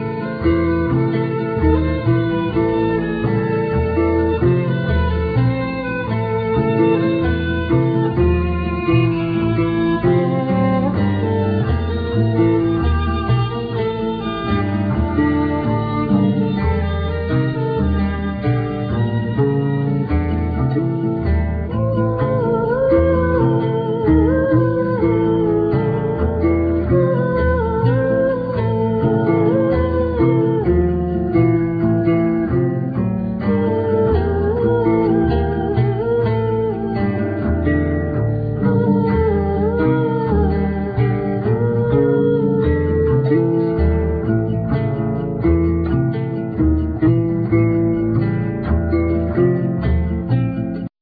Vocals,Violin
Winds,Bass,Percussions,etc